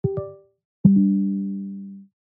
Usb In Out Téléchargement d'Effet Sonore
Usb In Out Bouton sonore